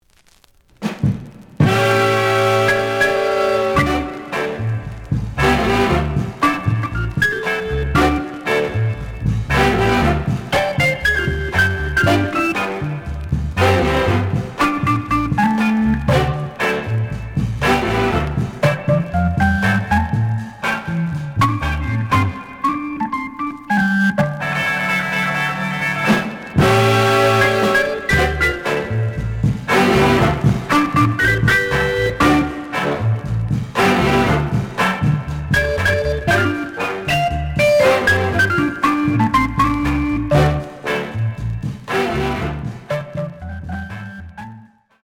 The audio sample is recorded from the actual item.
●Genre: Soul, 60's Soul
B side is slight cracking sound.